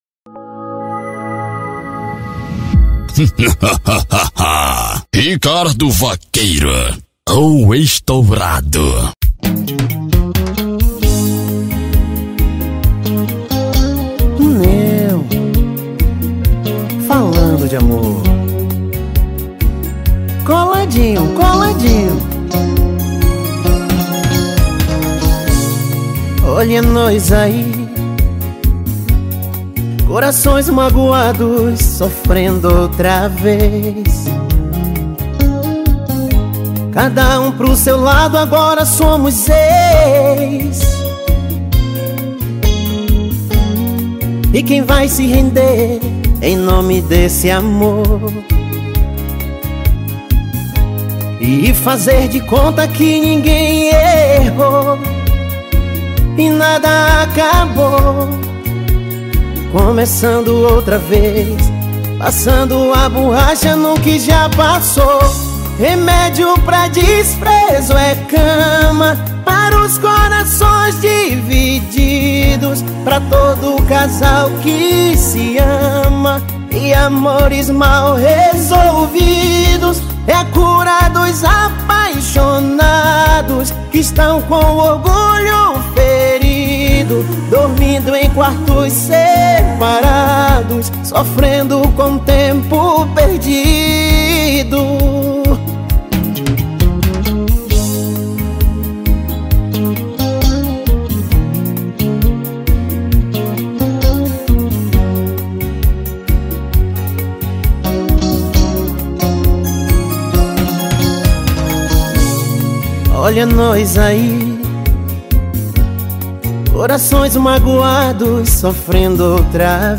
brega rasgado